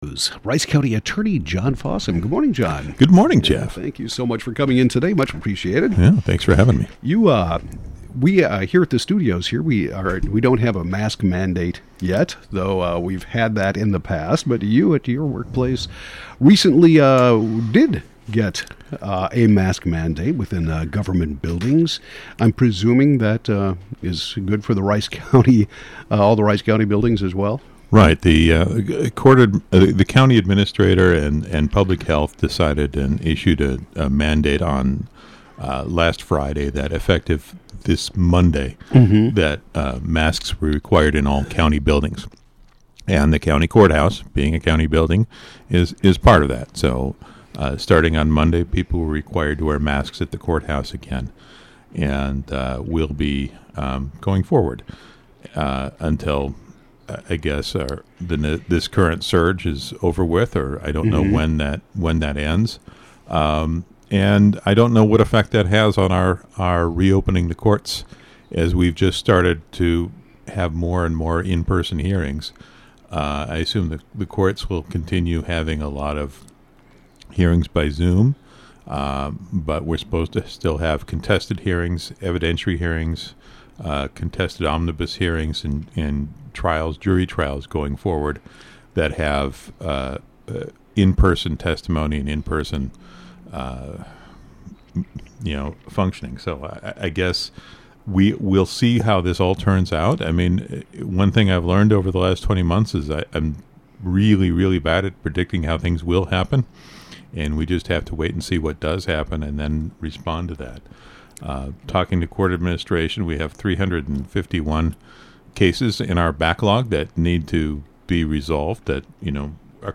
Rice County Attorney John Fossum discusses the possible affects of the mask mandate on the Rice County Court System, the court backlog, re-districting, and the new jail.